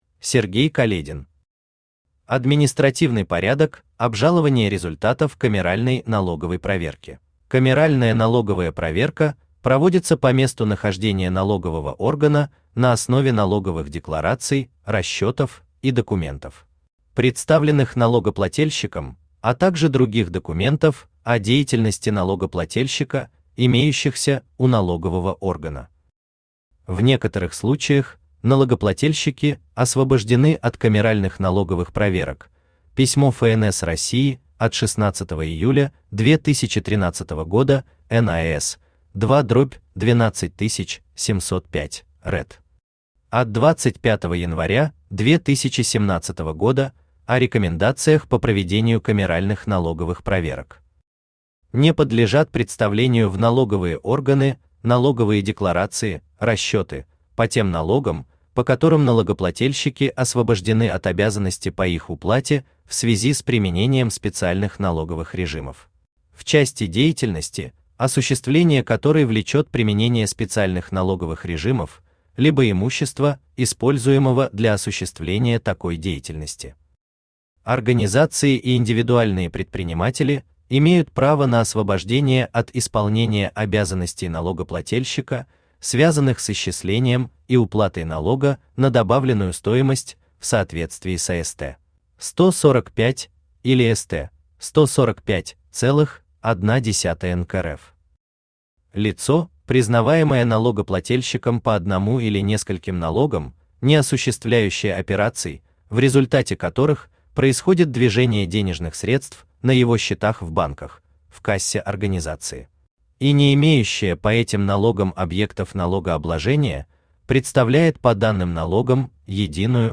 Аудиокнига Административный порядок обжалования результатов камеральной налоговой проверки | Библиотека аудиокниг
Aудиокнига Административный порядок обжалования результатов камеральной налоговой проверки Автор Сергей Каледин Читает аудиокнигу Авточтец ЛитРес.